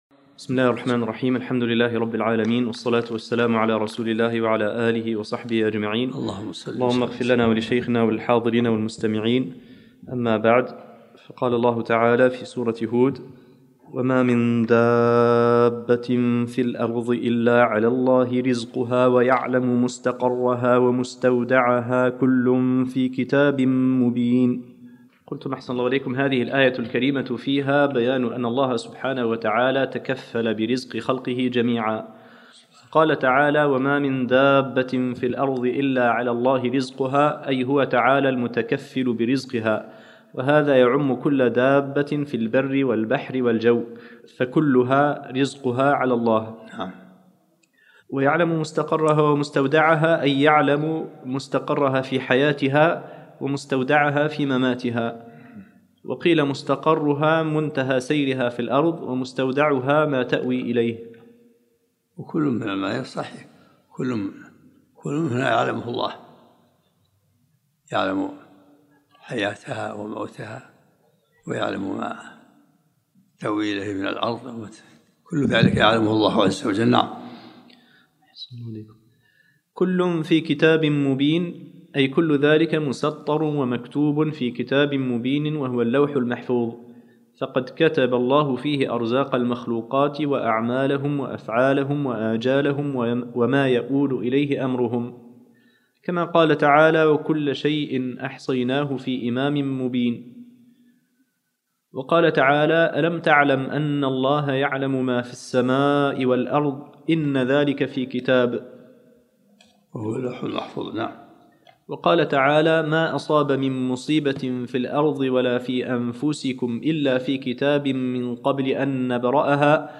الدرس الثاني من سورة هود